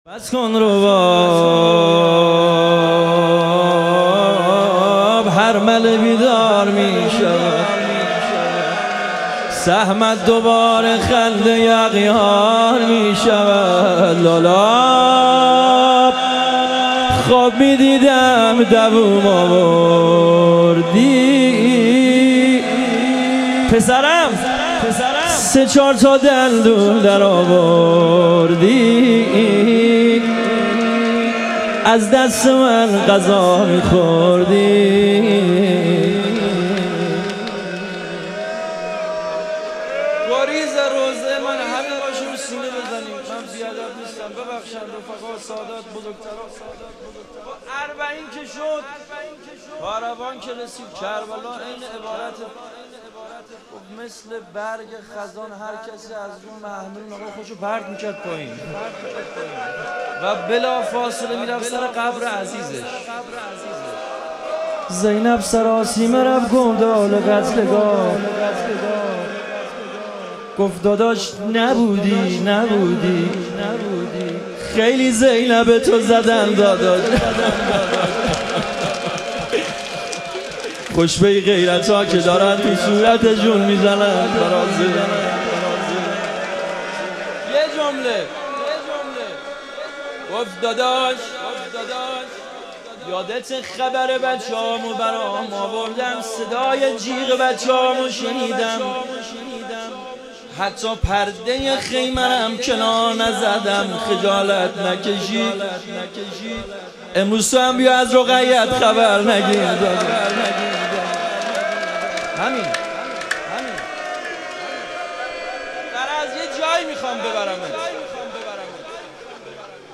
شهادت امام کاظم علیه السلام - روضه - 1 - 1403